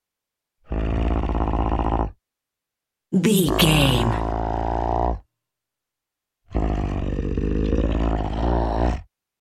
Monster growl snarl medium creature x3
Sound Effects
scary
ominous
angry